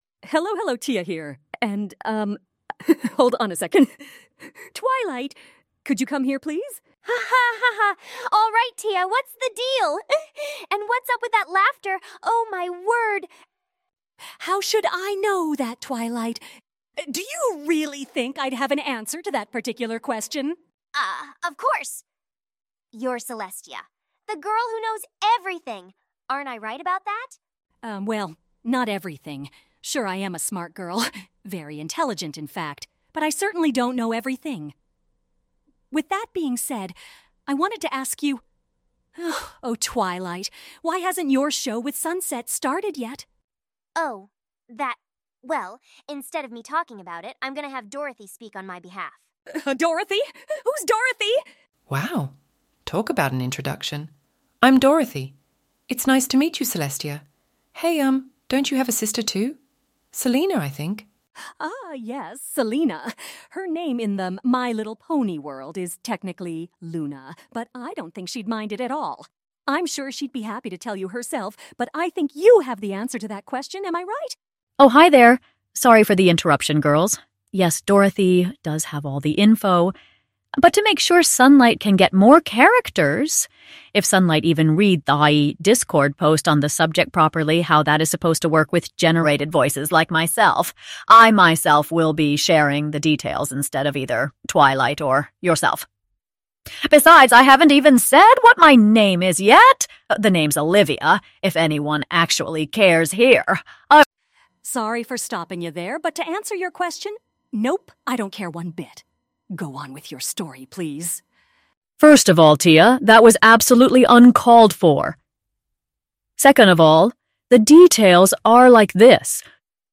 Among the following update, you'll here two of them, and one generated voice.